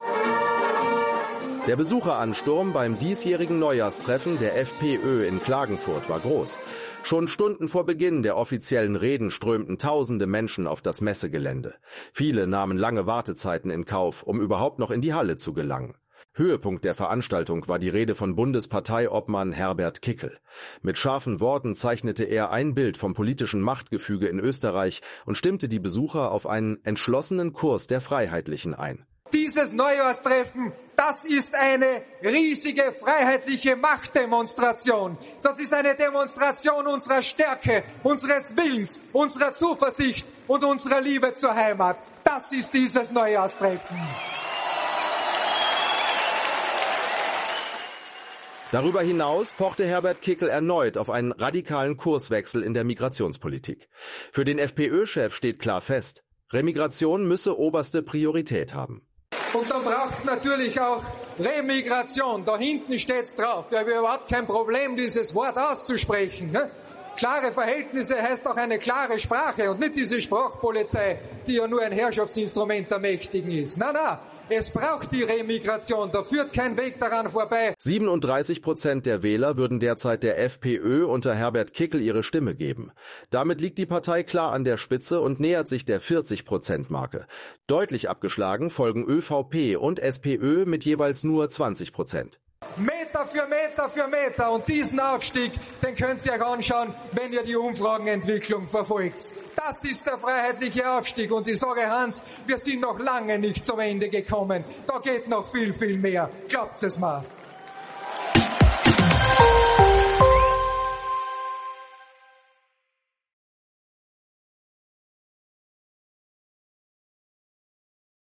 Am Wochenende fand in der Klagenfurter Messehalle das
Freiheitlichen aus – AUF1 war vor Ort.